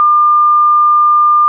wav_mono_22khz_impulse_sample.wav